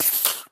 creeper4.ogg